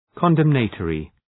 Προφορά
{kən’demnə,tɔ:rı}